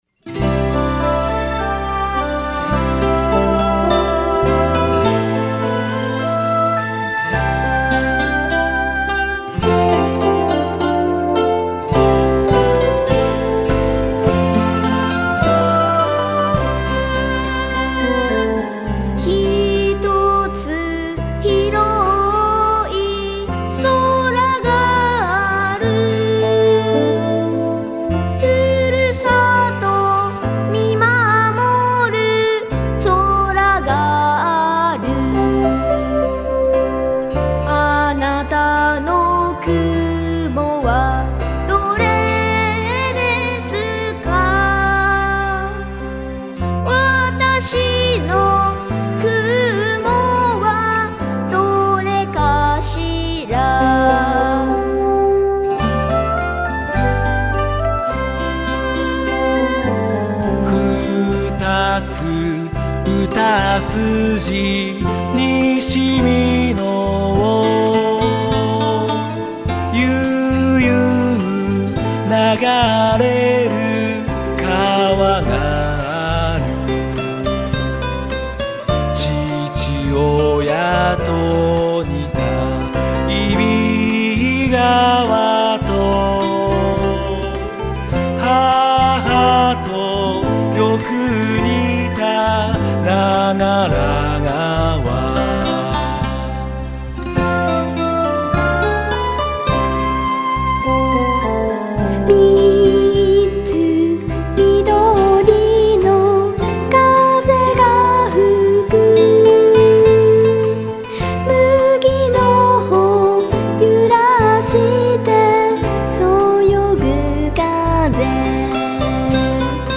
このイメージソングは、町の名所や町民の笑顔と温かさなどが歌詞に込められ、優しく流れるような曲調です。